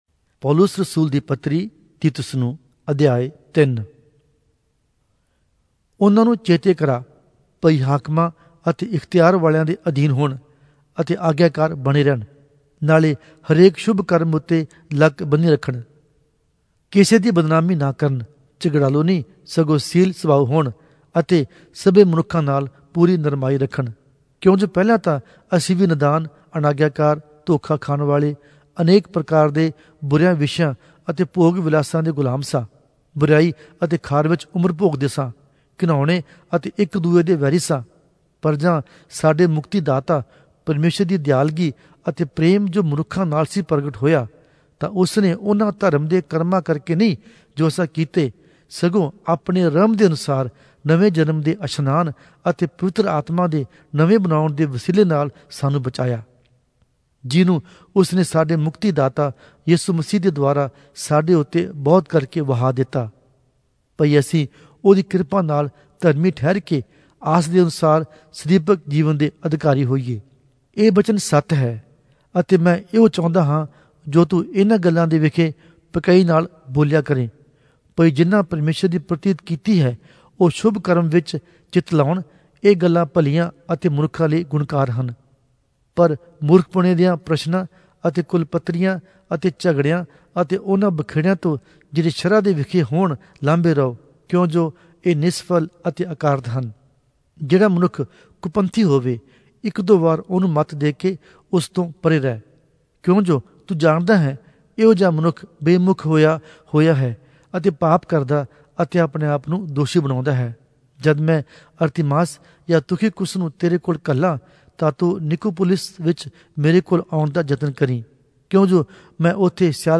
Punjabi Audio Bible - Titus 3 in Lxxen bible version